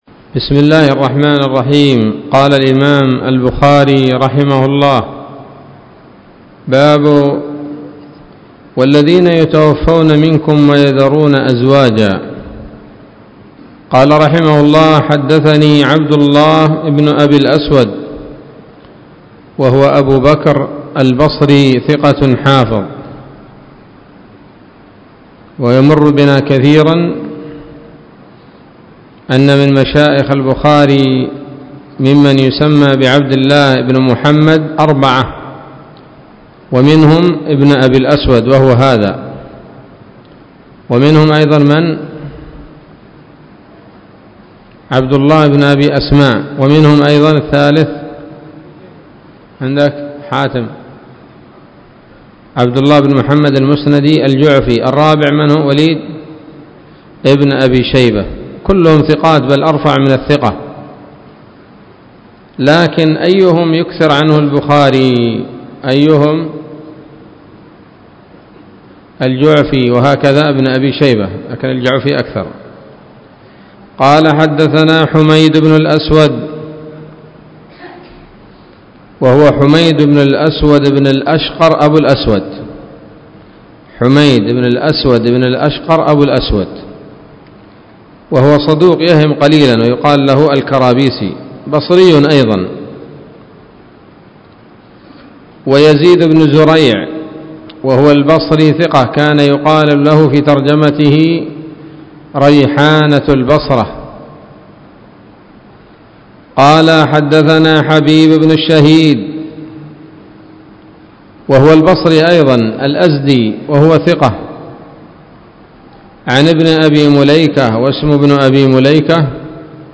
الدرس الثامن والثلاثون من كتاب التفسير من صحيح الإمام البخاري